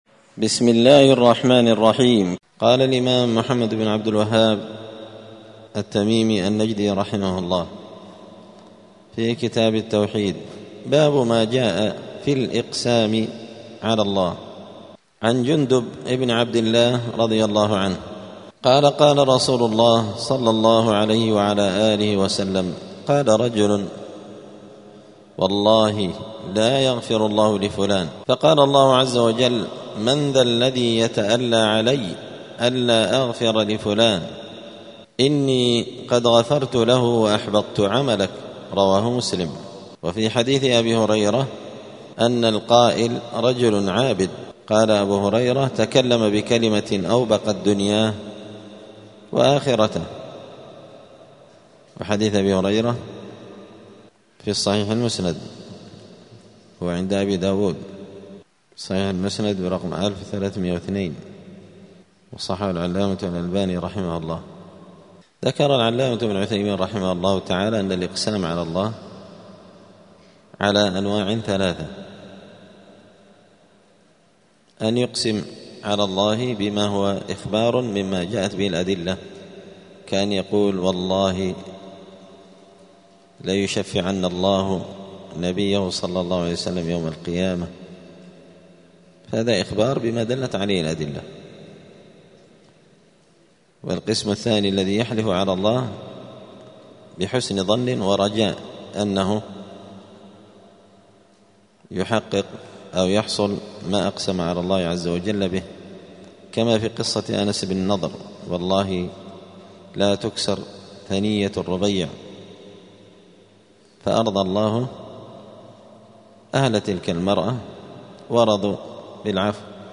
دار الحديث السلفية بمسجد الفرقان قشن المهرة اليمن
*الدرس الواحد والخمسون بعد المائة (151) {باب ما جاء في الإقسام على الله}*